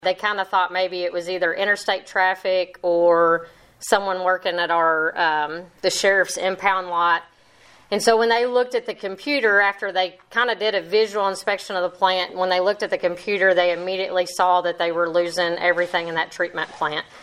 During Monday’s Pennyrile Area Development District January session, Lyon County Judge-Executive Jaime Green-Smith gave a considerable recount of the events that transpired, and what’s to come of the sequential batch reactor tank in the coming months.